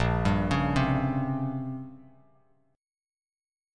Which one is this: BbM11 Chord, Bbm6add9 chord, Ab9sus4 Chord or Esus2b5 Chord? Bbm6add9 chord